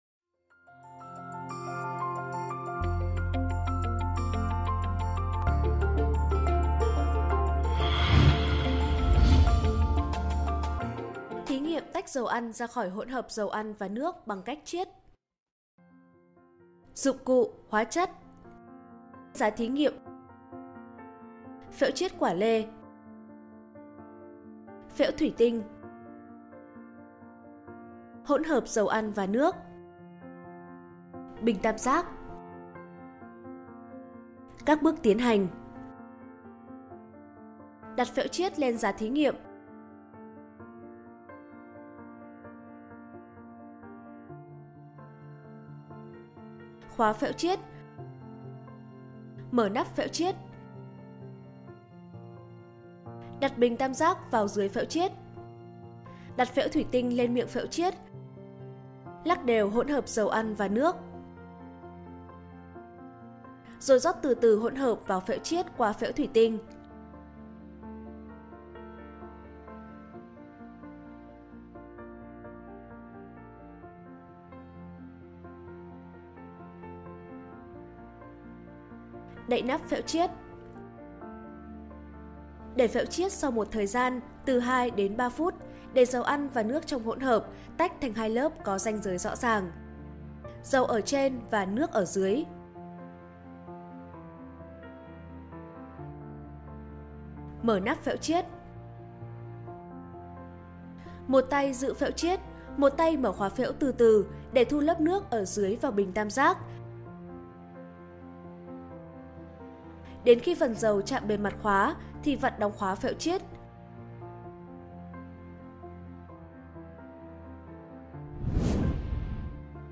Sách nói | Khoa học tự nhiên 6 - Tách dầu ăn ra khỏi hỗn hợp